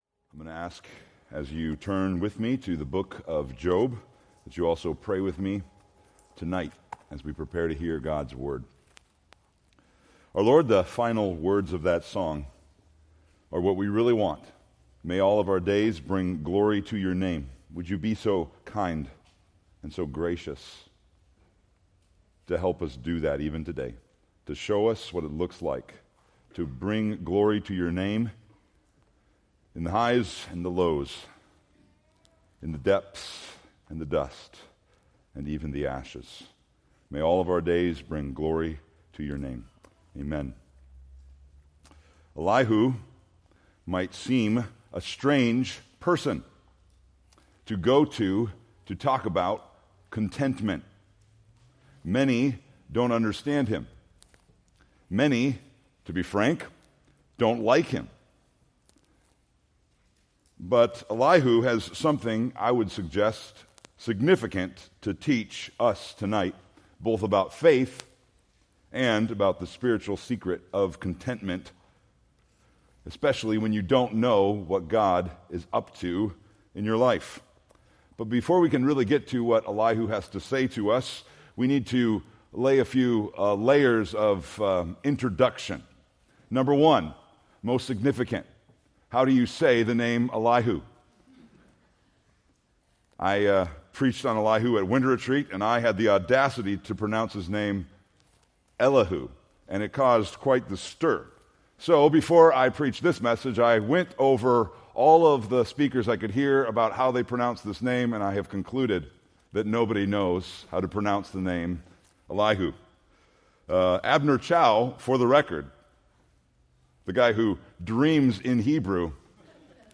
Preached March 16, 2025 from Job 32-42